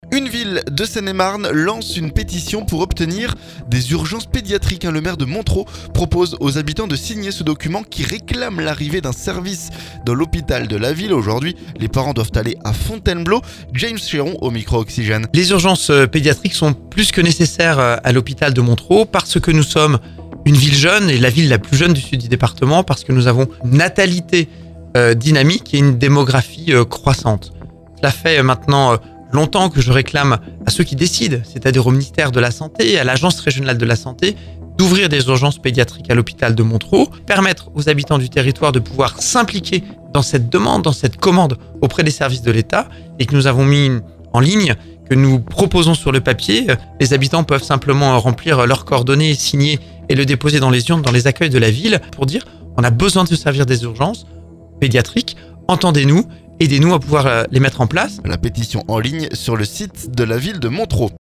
James Chéron au micro Oxygène.